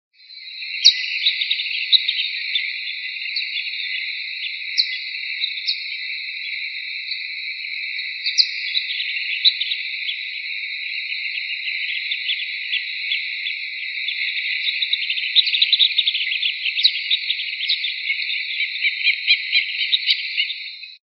Collared Plover (Anarhynchus collaris)
Life Stage: Adult
Location or protected area: Reserva Ecológica Costanera Sur (RECS)
Condition: Wild
Certainty: Recorded vocal
Recs.Chorlitos-de-collar.mp3